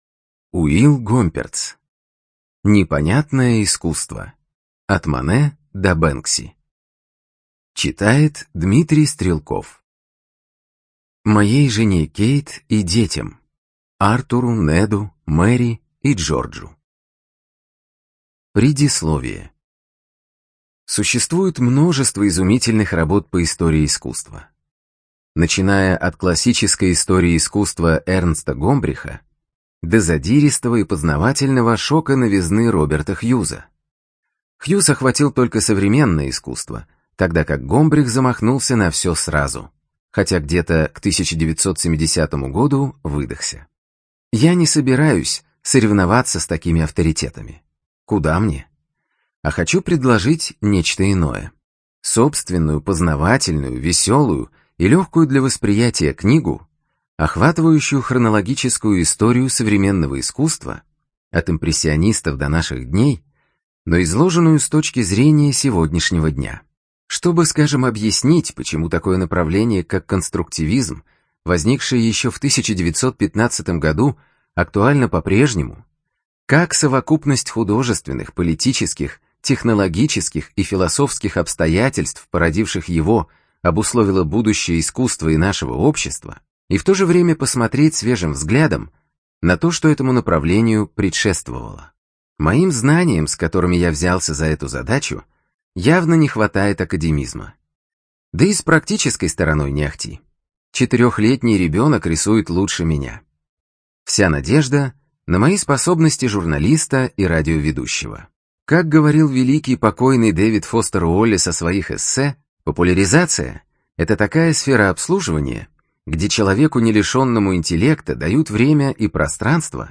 Студия звукозаписиСиндбад